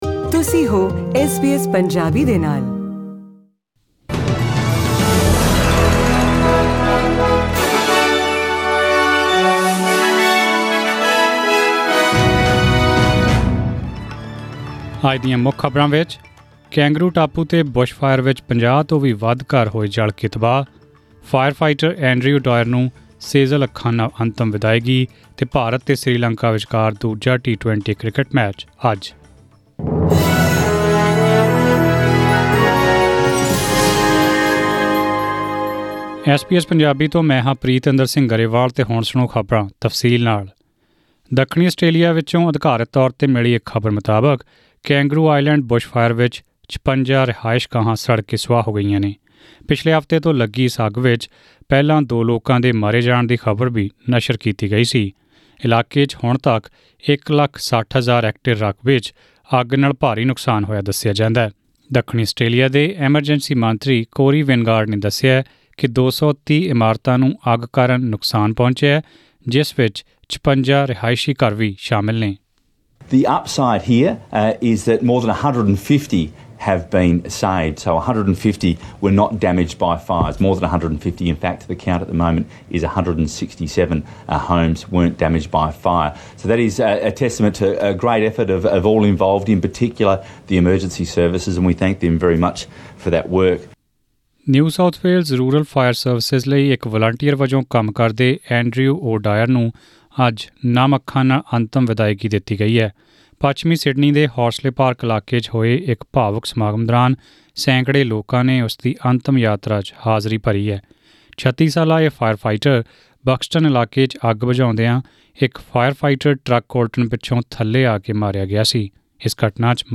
In today’s news bulletin...